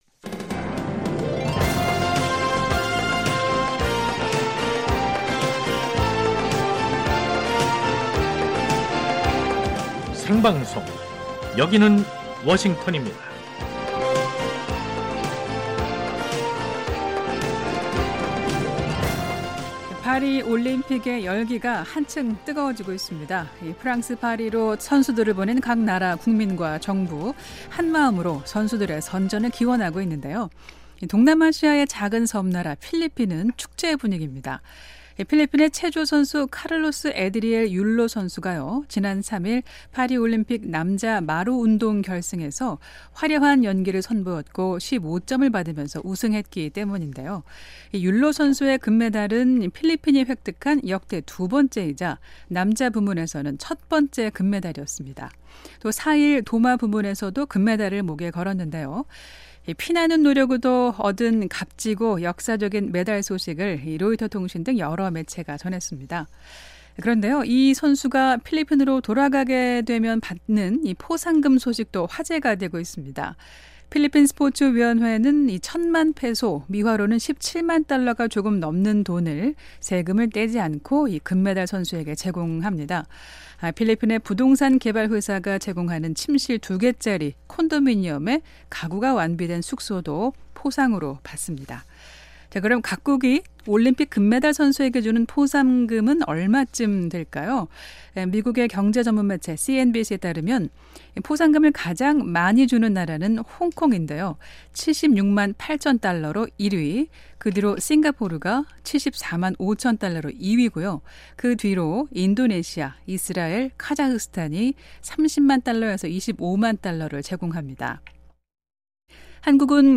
세계 뉴스와 함께 미국의 모든 것을 소개하는 '생방송 여기는 워싱턴입니다', 2024년 8월 7일 아침 방송입니다. 미국 민주당 대선 후보로 확정된 카멀라 해리스 부통령이 러닝메이트인 부통령 후보로 팀 월즈 미네소타 주지사를 선택했습니다. 이스라엘을 겨냥한 이란의 보복이 임박한 가운데 조 바이든 미국 대통령이 국가안보회의를 소집했습니다.